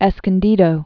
(ĕskən-dēdō)